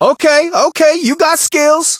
brock_death_03.ogg